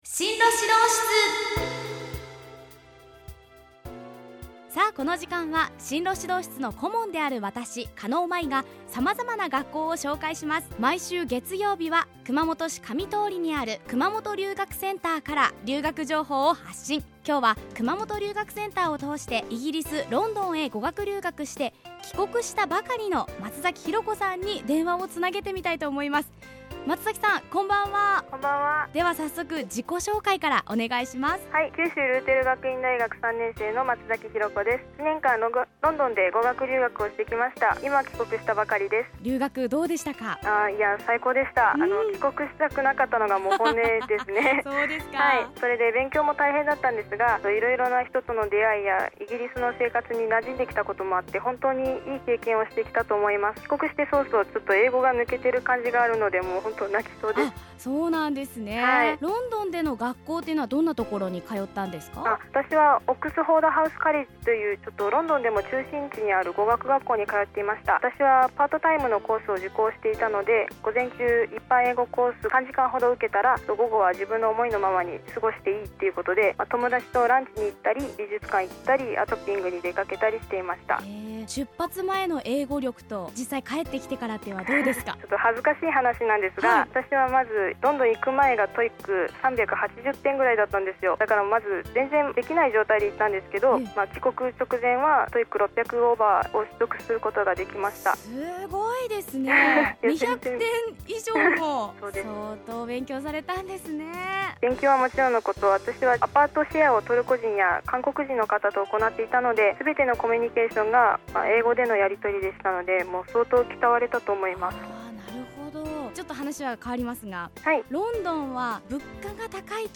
FMラジオ局・エフエム熊本のポッドキャスト。